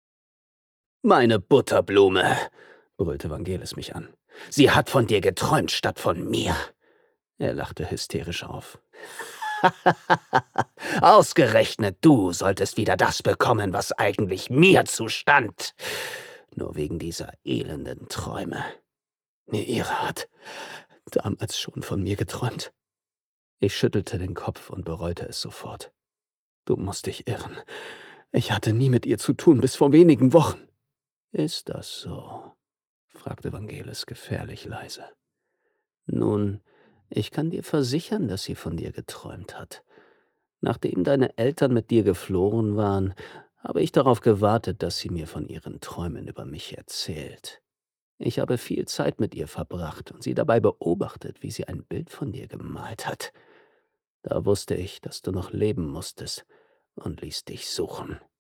Stimmfarbe: tief, bassig, warm, vertraut, sinnlich